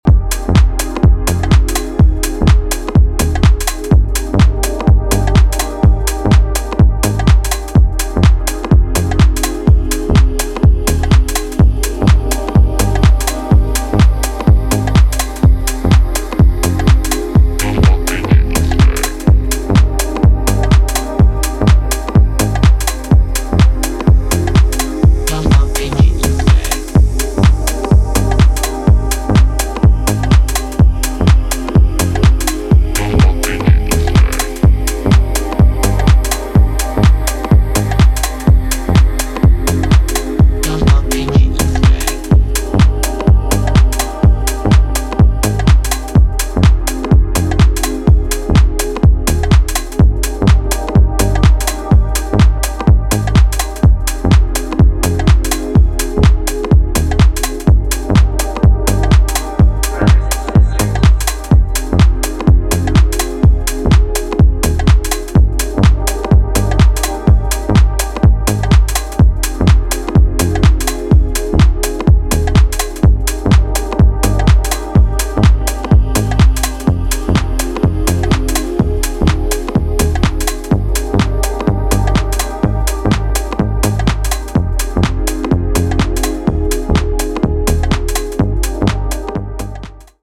浸透性の高いシンセコードにヴォコーダー・ヴォイスが絡んだ、流行り廃りなくプレイできそうな秀作です。